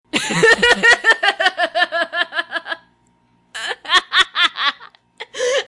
Download Funny sound effect for free.